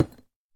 Minecraft Version Minecraft Version snapshot Latest Release | Latest Snapshot snapshot / assets / minecraft / sounds / block / deepslate / place6.ogg Compare With Compare With Latest Release | Latest Snapshot